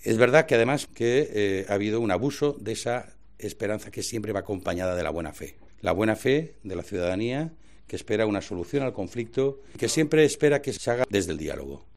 En una comparecencia en la sede socialista, Ábalos ha reprochado a Puigdemont que haya hecho primero esa llamada al diálogo para después firmar con el resto de independentistas un manifiesto en el que pretenden que se trate a Cataluña con la consideración de un estado independiente que negocie "de igual a igual" con España.